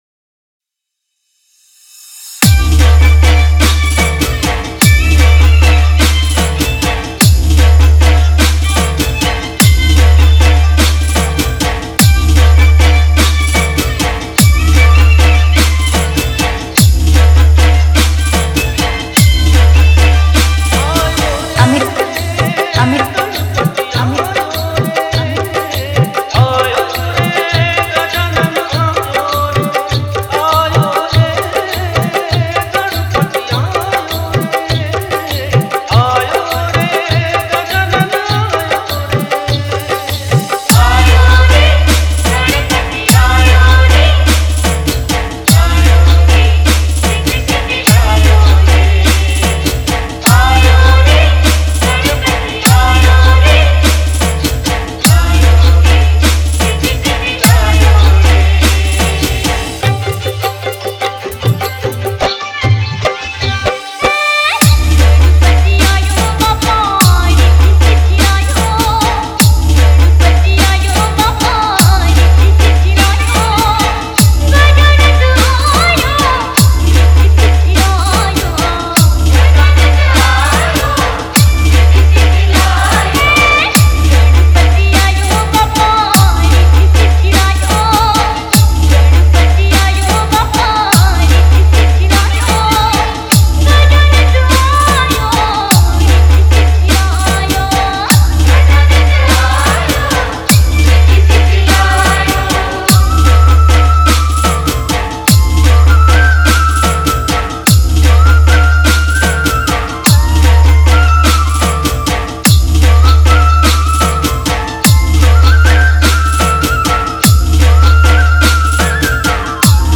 Ganesh Chaturthi Dj Remix Song